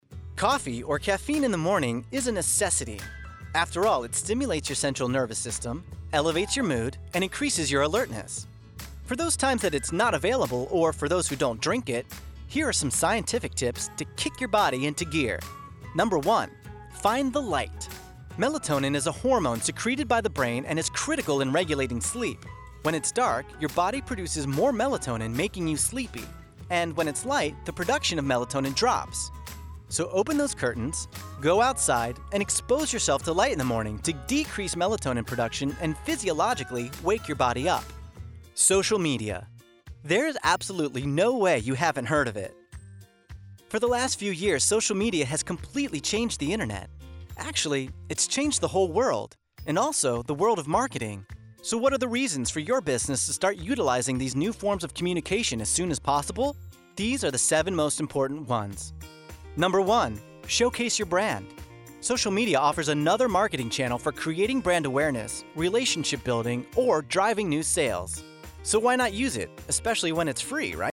Explainer Demo
Young Adult
Middle Aged